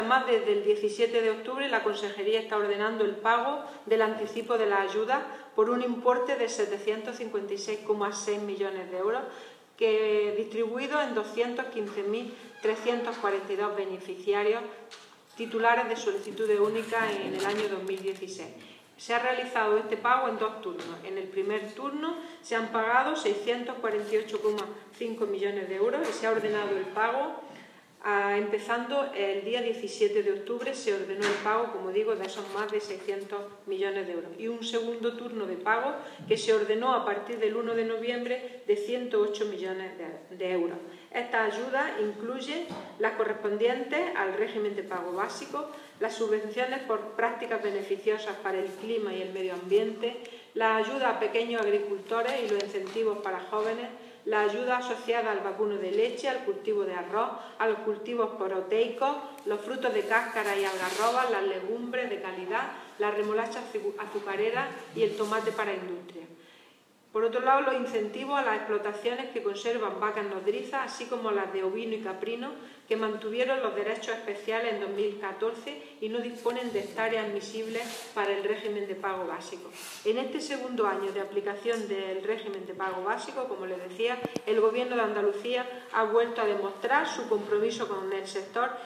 Carmen Ortiz durante la Comisión de Agricultura, Pesca y Desarrollo Rural
Declaraciones de Carmen Ortiz sobre adelanto de ayudas de la PAC